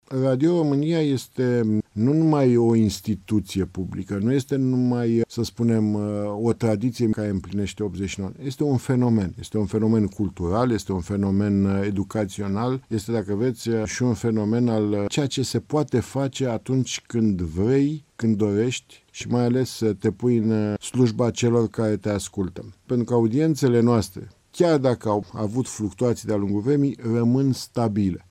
„Radio România este mai mult decât un post de radio; este un fenomen”, declară preşedintele director general al Societăţii Române de Radiodifuziune, Georgică Severin: